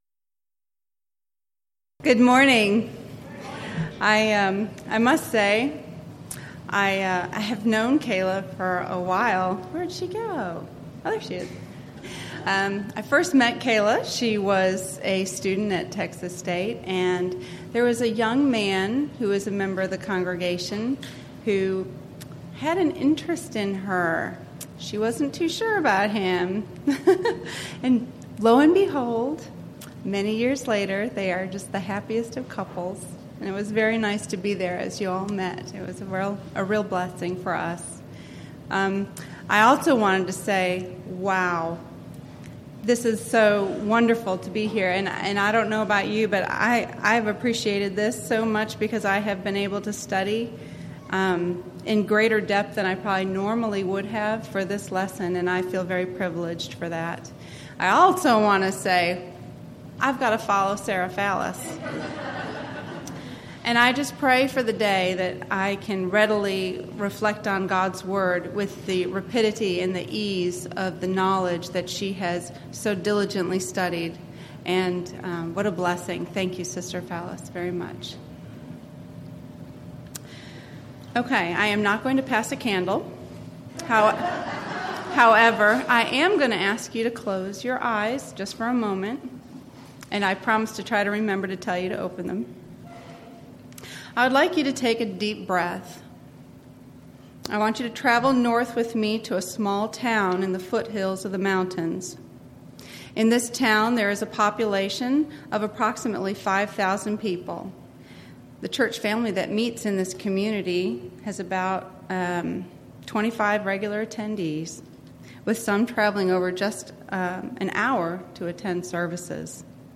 Event: 2014 Texas Ladies in Christ Retreat
Ladies Sessions